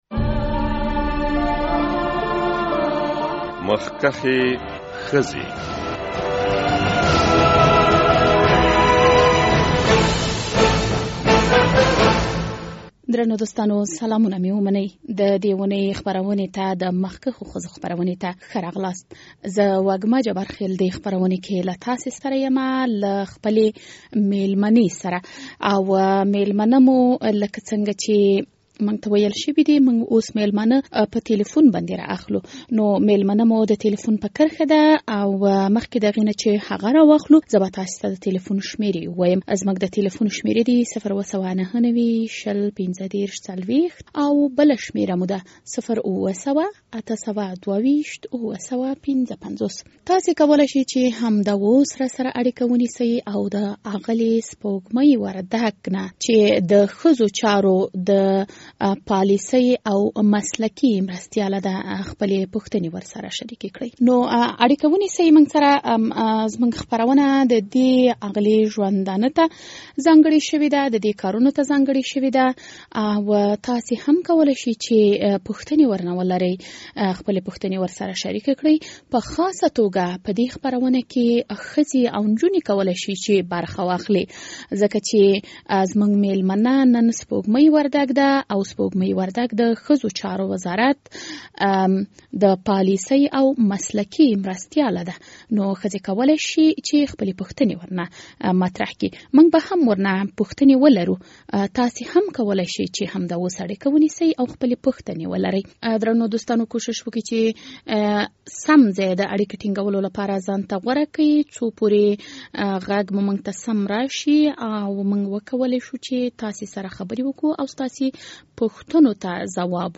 اغلې وردګ د خپل سیاسي او فرهنګي ژوند په اړه معلومات وړاندې کوي او د اورېدونکو پوښتنو ته هم ځوابونه وایي.